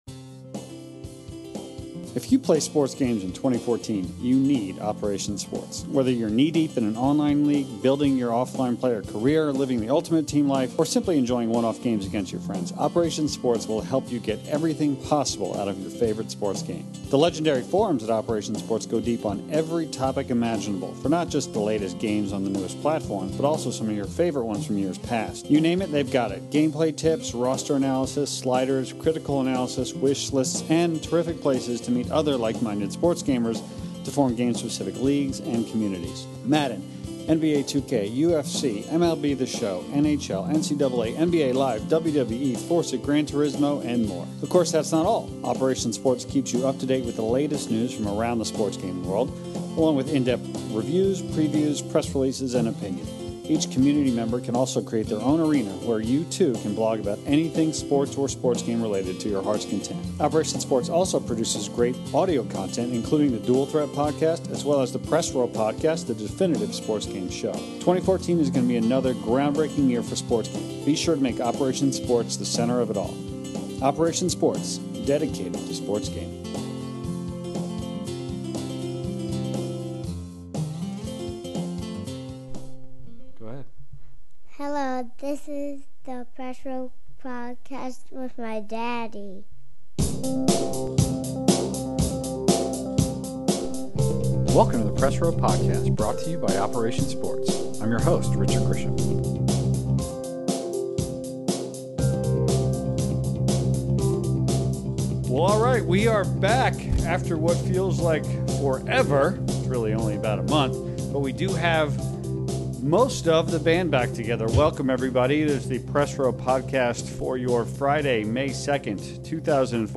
The 82nd edition of the Press Row Podcast features most of our regular panel back in action after trips across the country to PAX East and elsewhere! MLB The Show kicks off the conversation as the panelists discuss their current experiences and anticipation for the upcoming PS4 release. This is followed by a look at the current state of arcade sports games, then a whole host of topics from questions submitted by listeners, including the Madden 15 trailer, next-gen NHL, and much more.